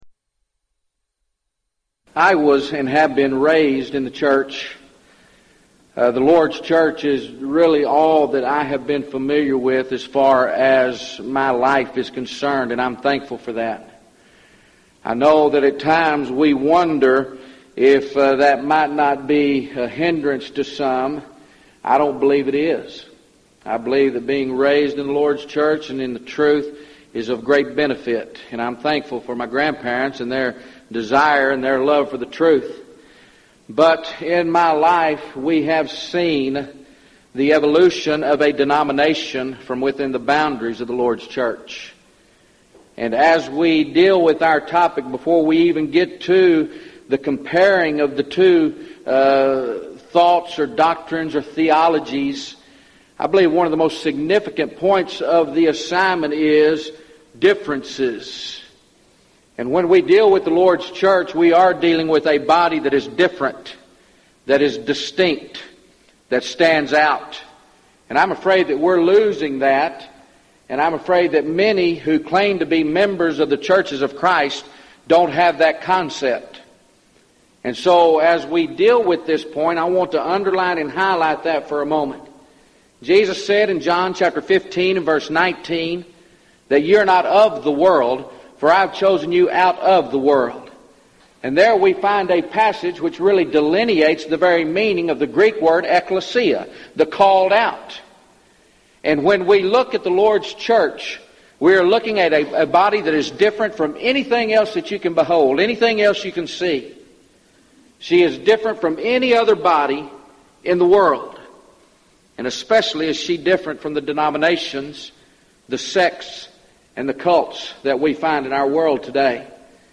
Event: 1999 Gulf Coast Lectures
lecture